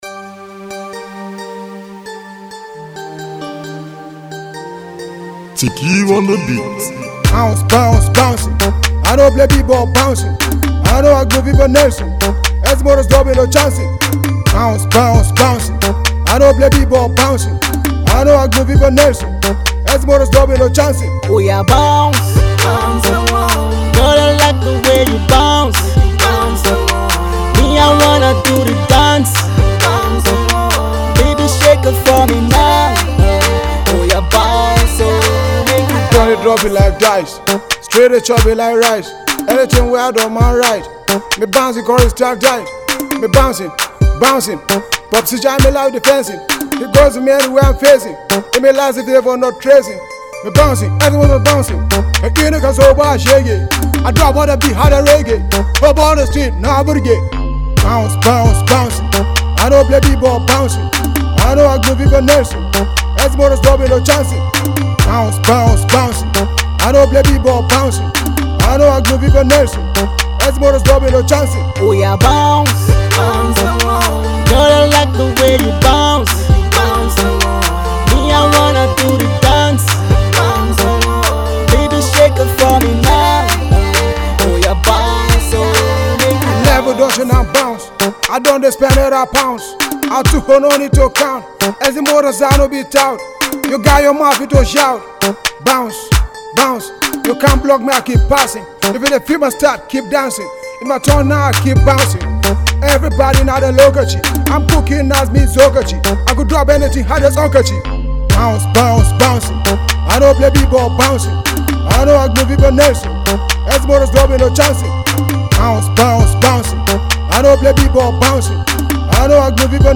"Trap" style of Rap